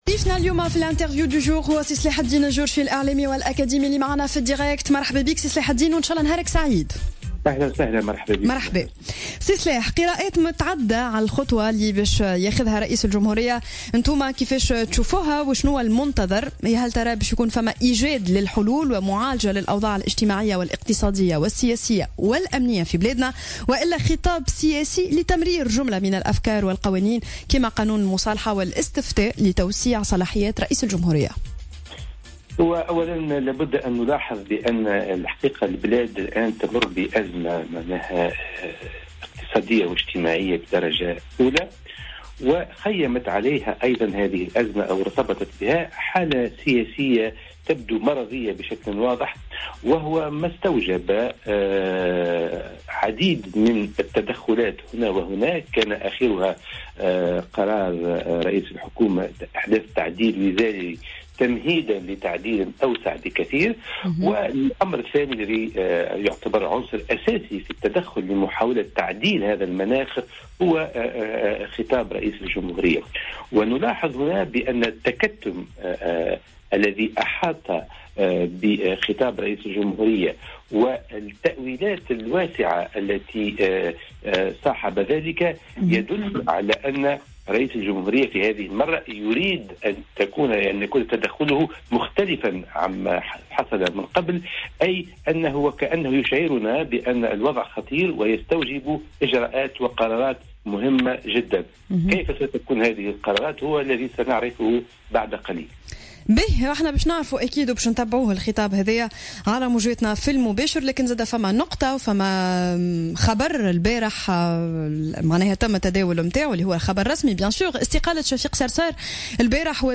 وأضاف الجورشي في مداخلة له اليوم في برنامج "صباح الورد" على "الجوهرة أف أم" :